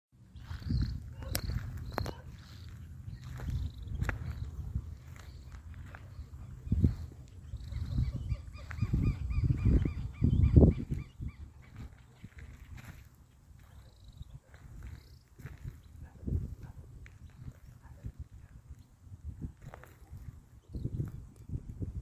Corredor-crestudo (Coryphistera alaudina)
Nome em Inglês: Lark-like Brushrunner
Localidade ou área protegida: Perilago Termas de Río -hondo
Condição: Selvagem
Certeza: Gravado Vocal